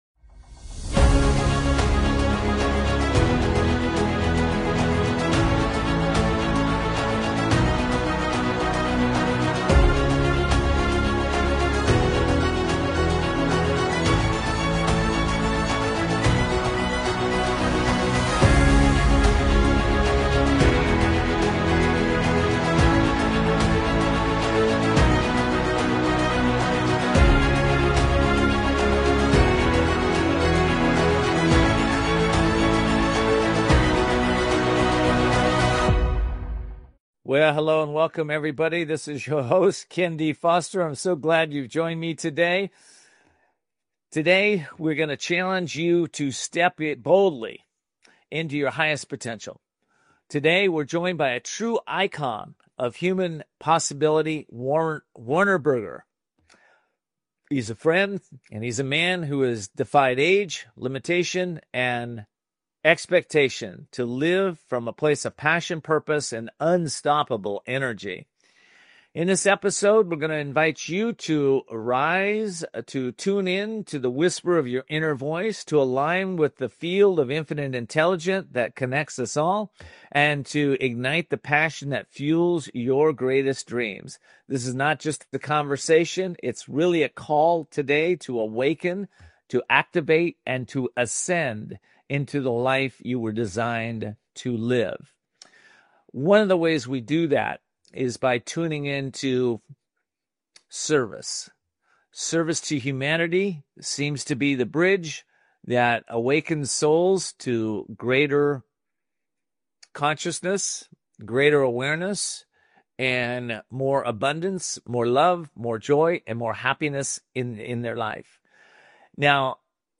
You’ll hear why real strength comes from connection, how micro-choices build daily courage, and why adventure is the ultimate teacher. This is more than a conversation.